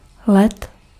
Ääntäminen
IPA: [vɔl]